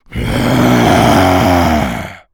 Male_Low_Growl_01.wav